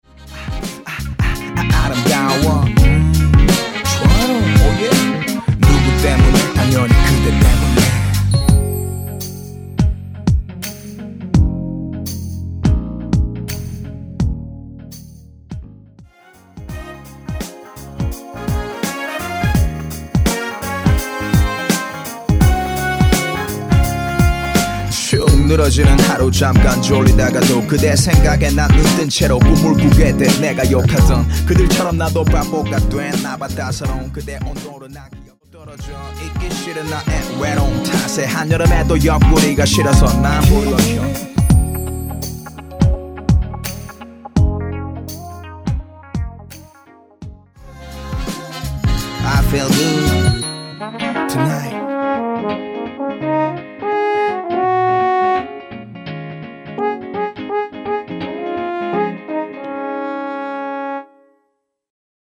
엔딩이 페이드 아웃 이라 엔딩부분 만들어 놓았습니다.
Db
앞부분30초, 뒷부분30초씩 편집해서 올려 드리고 있습니다.